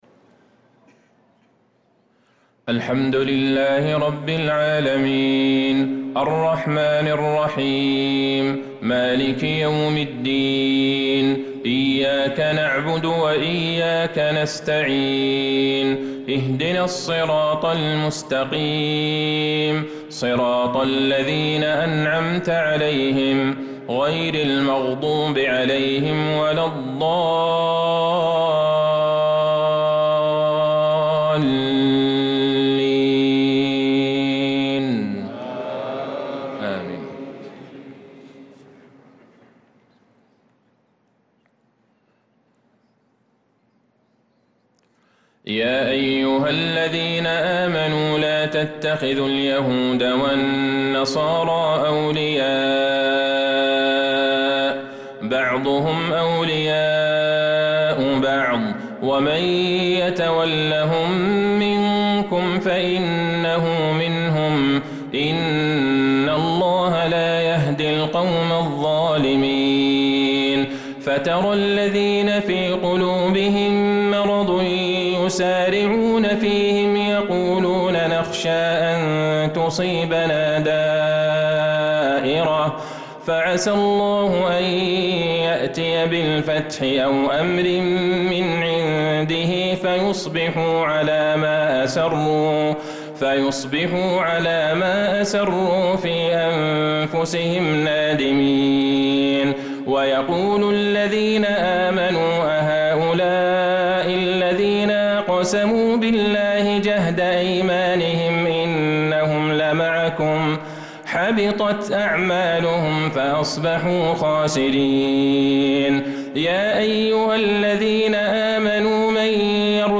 صلاة العشاء للقارئ عبدالله البعيجان 17 ذو القعدة 1445 هـ